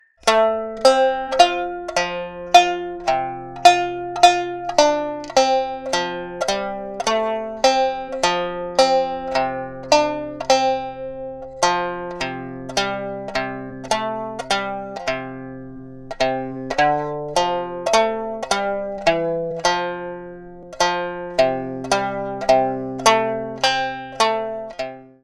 Anfänger-freundliches Lied für Shamisen.
• Honchōshi Stimmung (C-F-C)